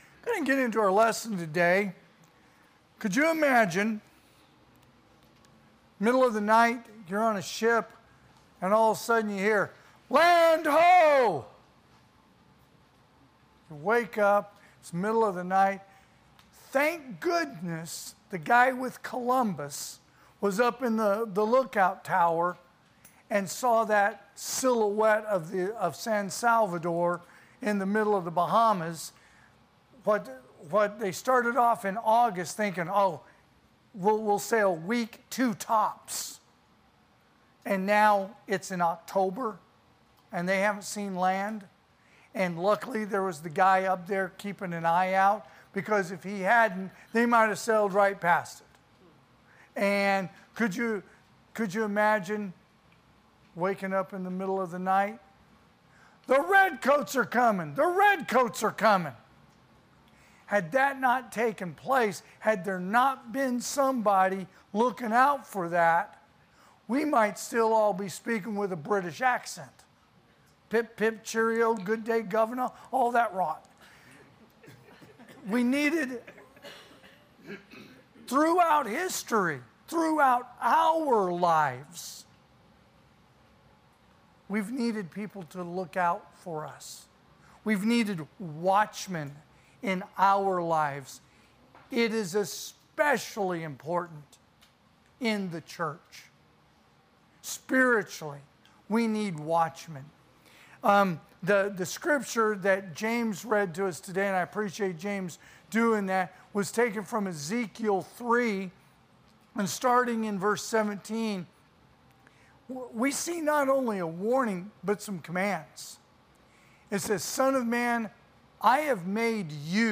AM Worship
Sermons